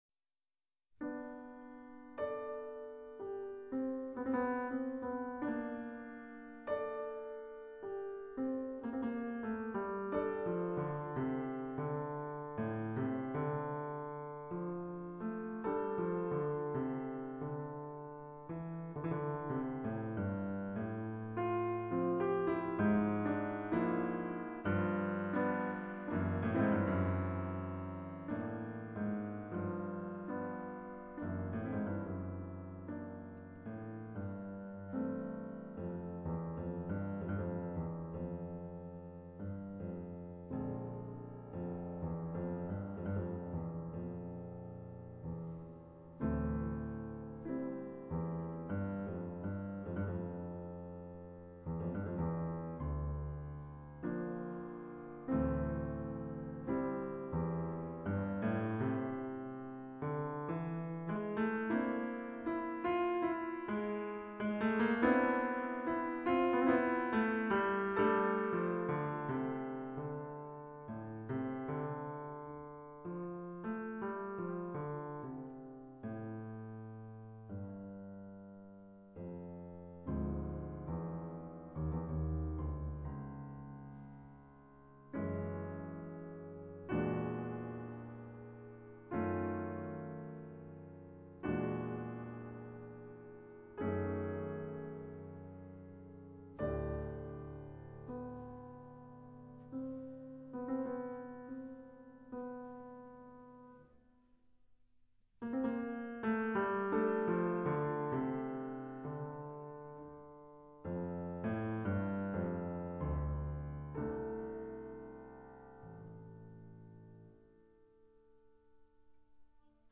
Meses após finalizar os 12 Études, escreve uma pequena peça de 21 compassos,
Élégie, rigorosamente de síntese, onde nenhuma concessão existe, a evidenciar profunda austeridade.